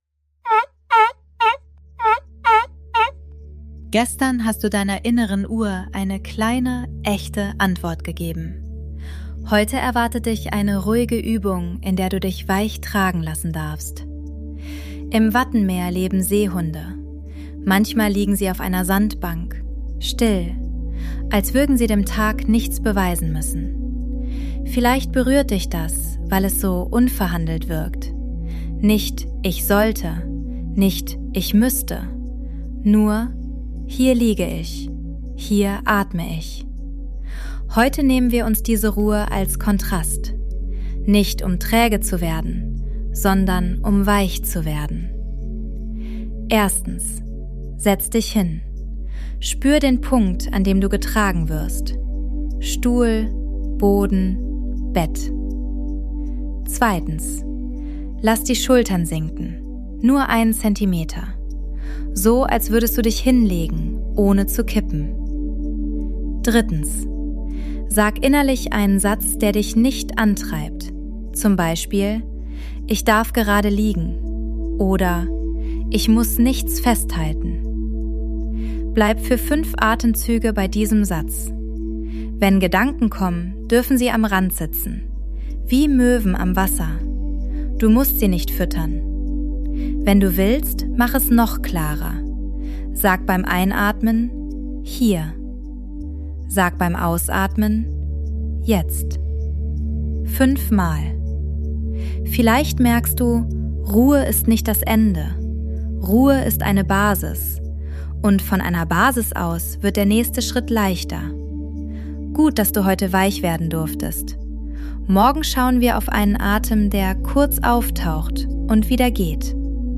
Juist Sounds & Mix: ElevenLabs und eigene Atmos